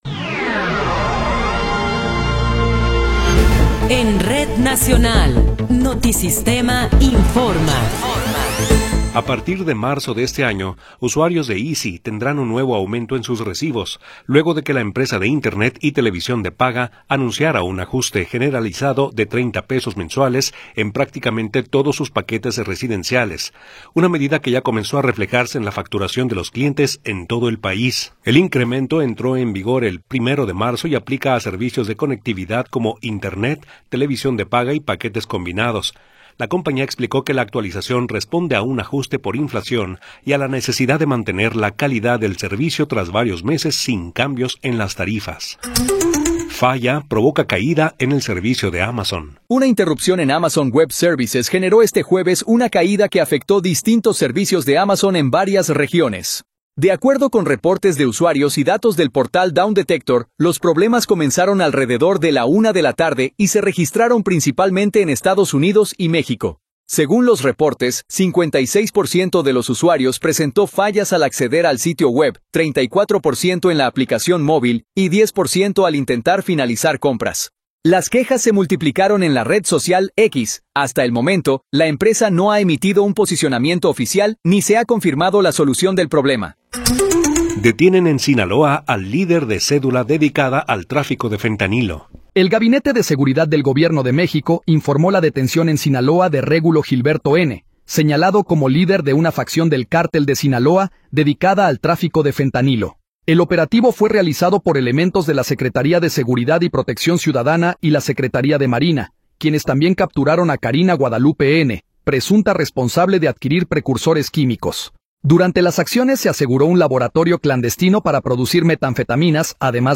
Noticiero 18 hrs. – 5 de Marzo de 2026
Resumen informativo Notisistema, la mejor y más completa información cada hora en la hora.